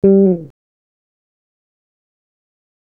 G FALL OFF.wav